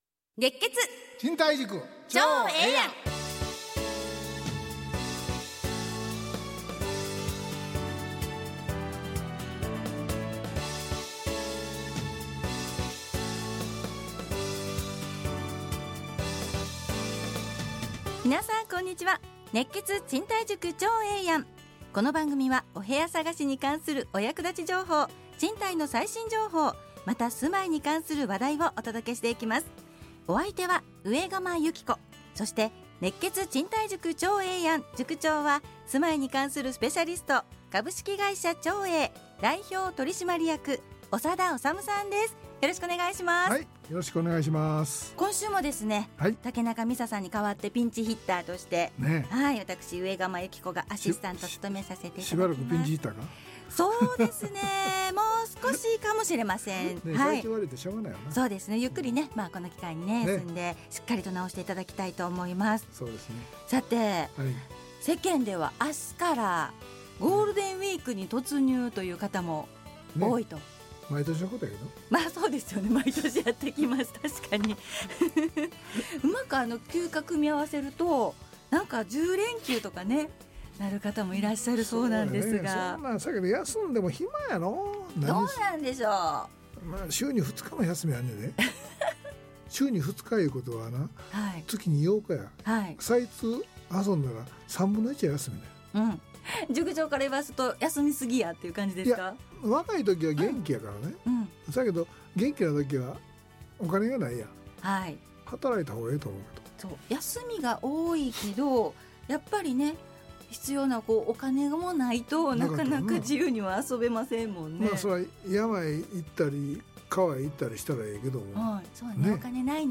ラジオ放送 2024-04-30 熱血！